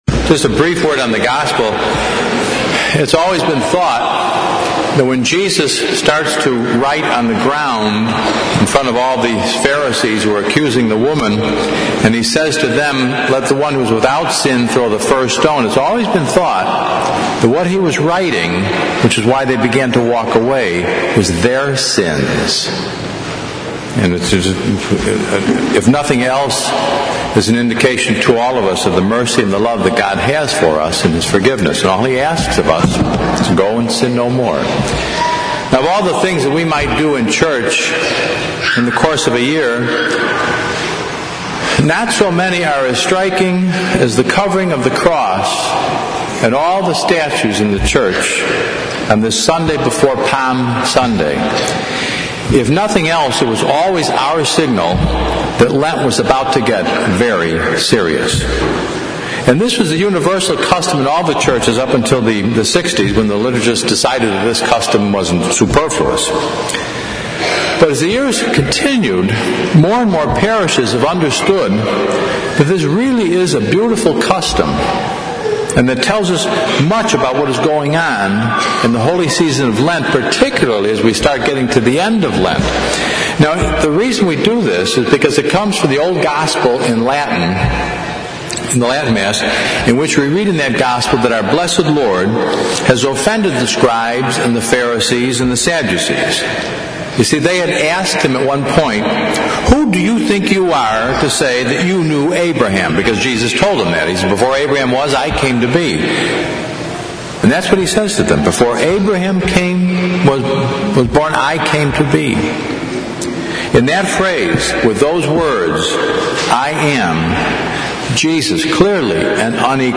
Fifth Sunday of Lent/Passion Sunday.